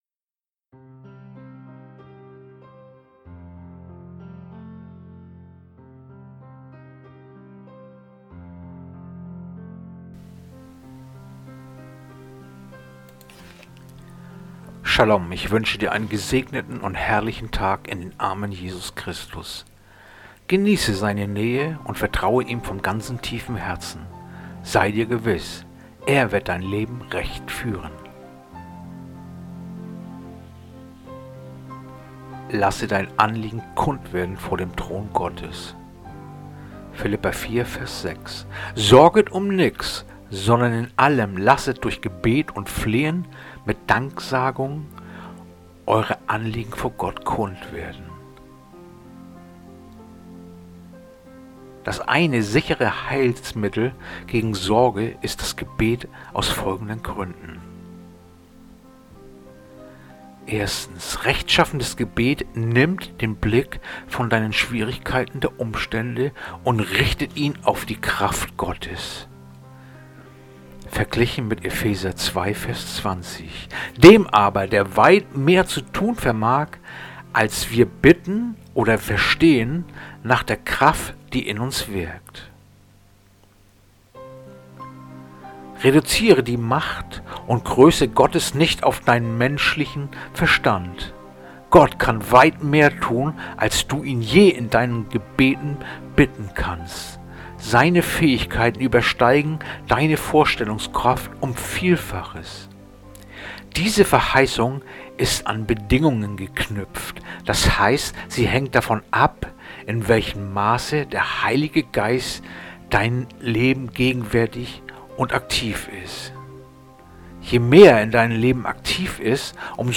heutige akustische Andacht
Andacht-vom-20.-Juni-Philipper-4-6.mp3